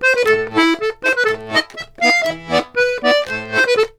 C120POLKA1-R.wav